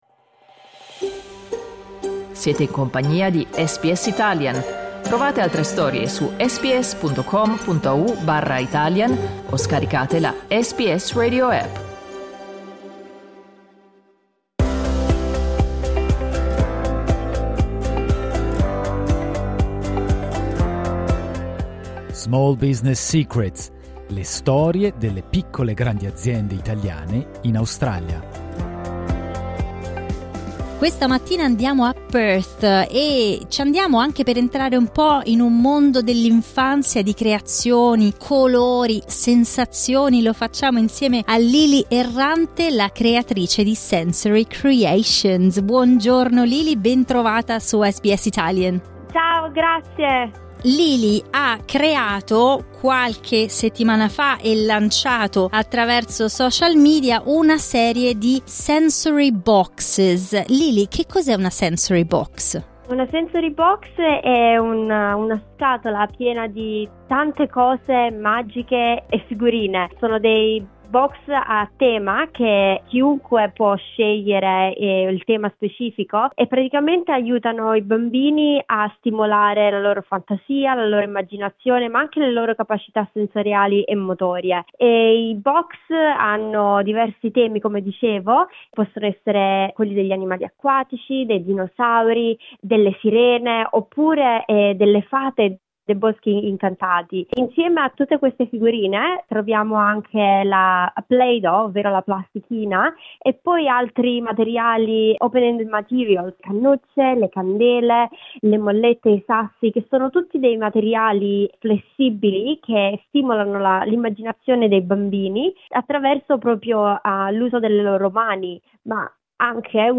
Ascolta l'intervista cliccando sul tasto "play" in alto Le scatole sono adatte a bimbi dai 2 ai 7 anni e sono composte da pasta da modellare realizzata con materiali naturali, piccole figurine e oggetti di uso comune.